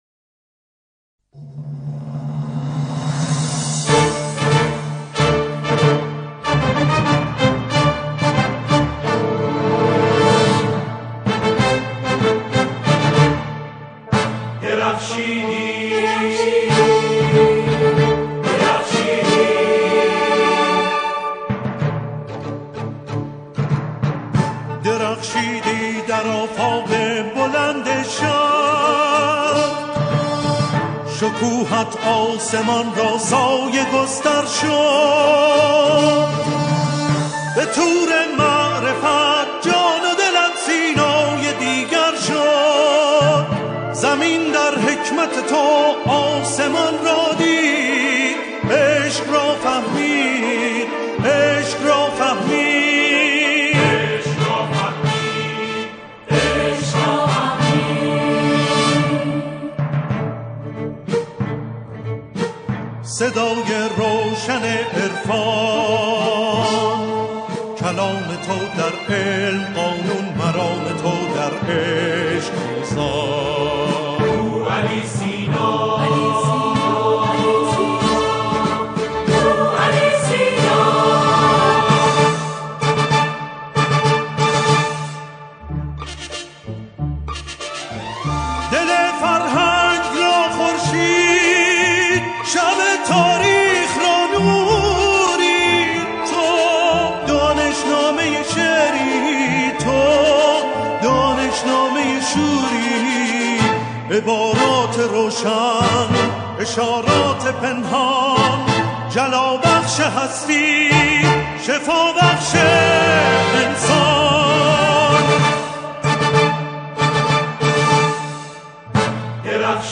در این قطعه، شعری با موضوع مشاهیر ایران همخوانی می‌شود.